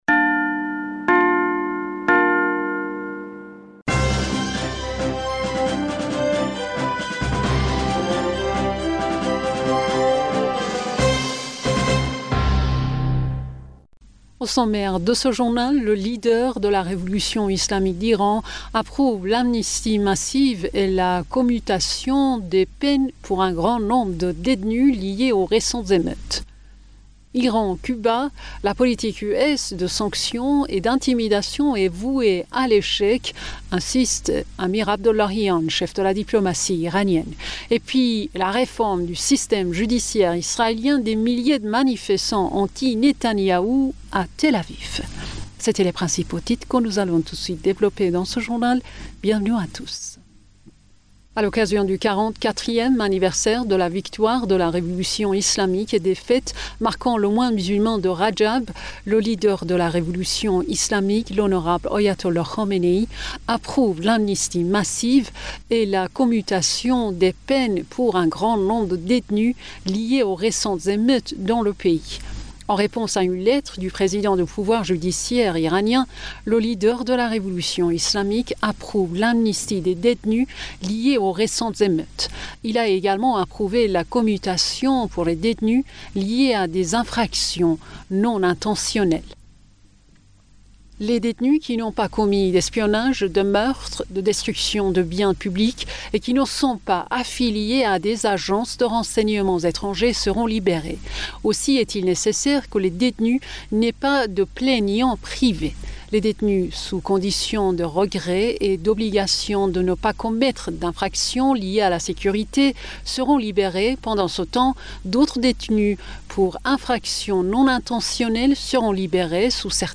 Bulletin d'information du 05 Février